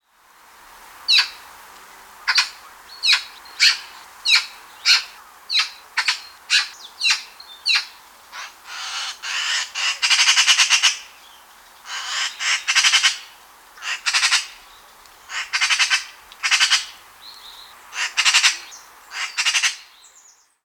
Elster
Verschiedene Elsternlaute
405-elster_diverse_laute-soundarchiv.com_.mp3